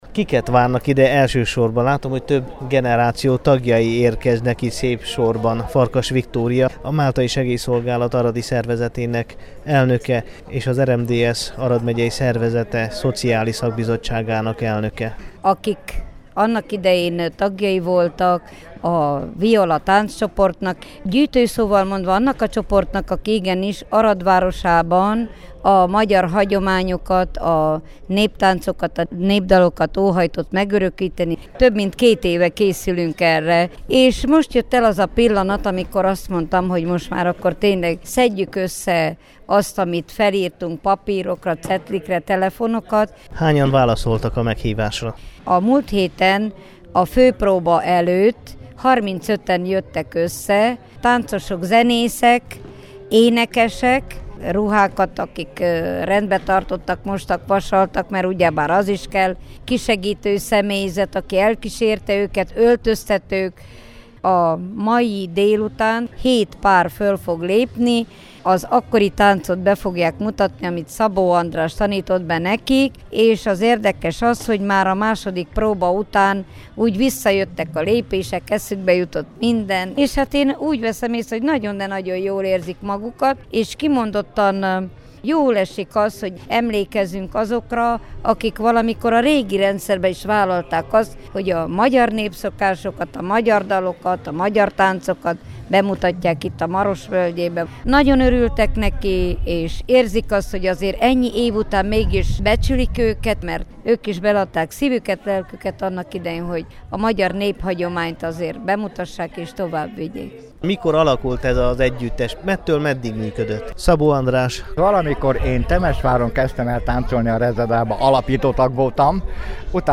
A jó hangulatú rendezvényen, amelyen a volt táncosok ismét fellépő ruhát húztak, a régi violások és rajongóik megtöltötték a Csiky Gergely Főgimnázium dísztermét csütörtök délután.
viola_neptencegyuttes_talalkozoja.mp3